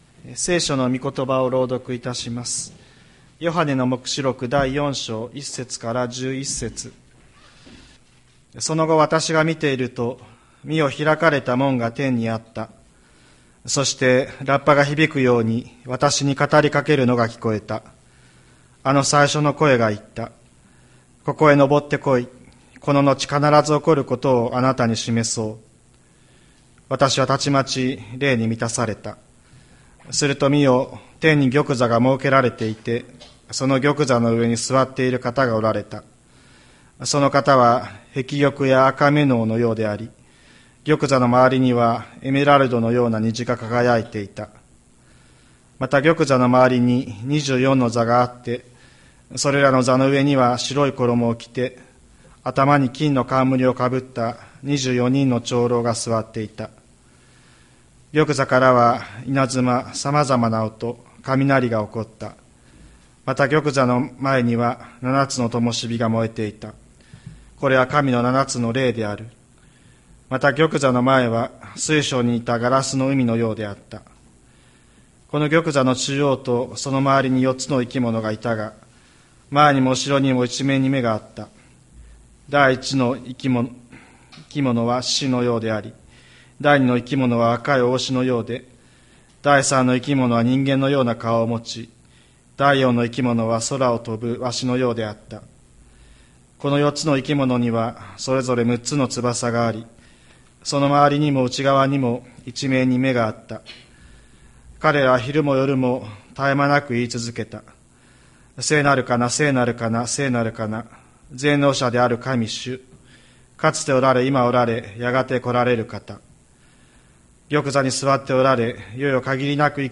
千里山教会 2024年09月22日の礼拝メッセージ。